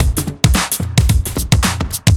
OTG_TripSwingMixB_110a.wav